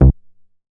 MoogMini 002.WAV